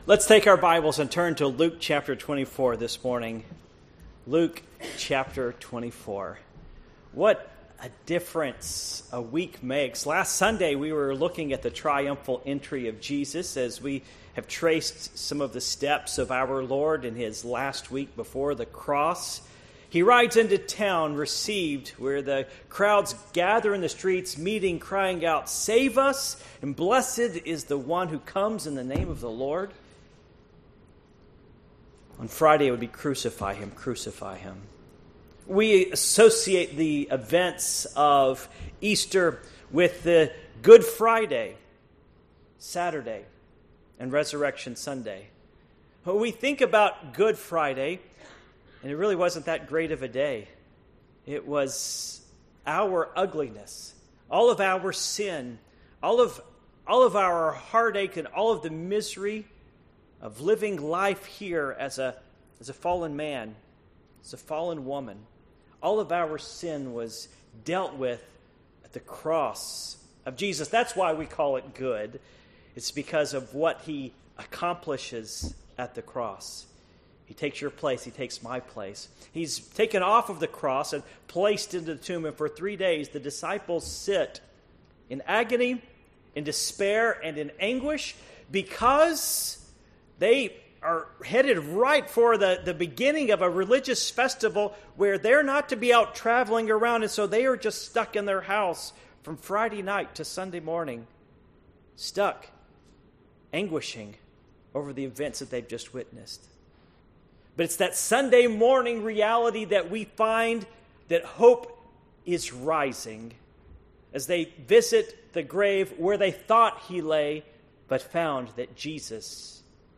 Passage: Luke 24:1-31 Service Type: Morning Worship